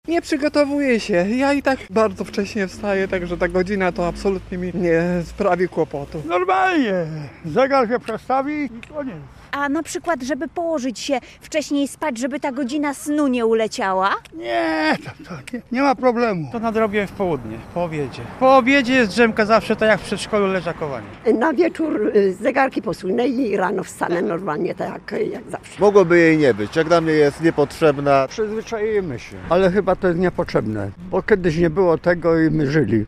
A jak na zmianę czasu reagują zwykli ludzie? Z mieszkańcami Konina rozmawiała nasza reporterka.